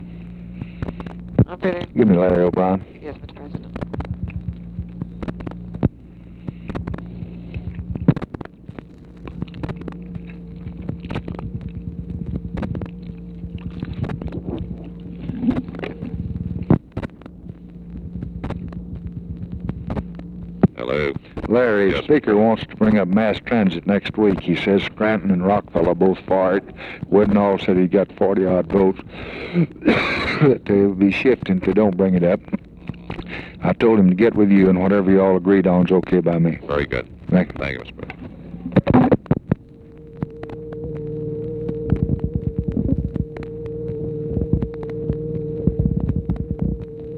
Conversation with LARRY O'BRIEN
Secret White House Tapes